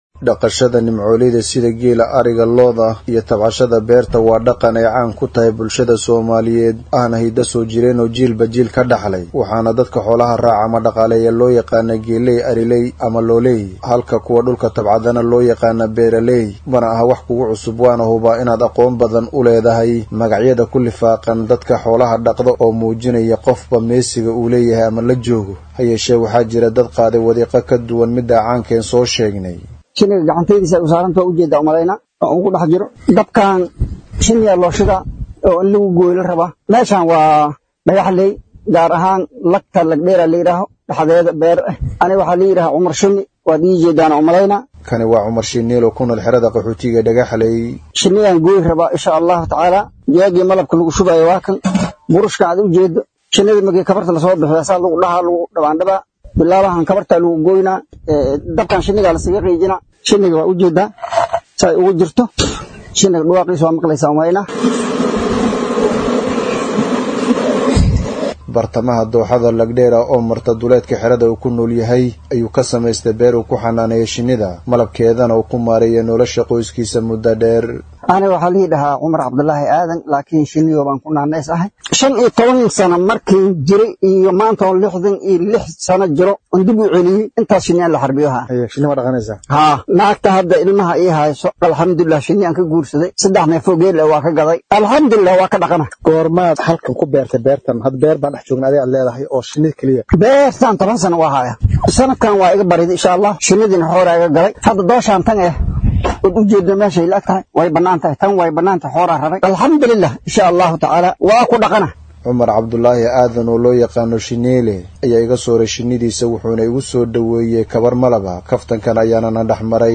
DHAGEYSO:Warbixin:Qof duleedka Dhagaxleey Shinni ku xanaaneeya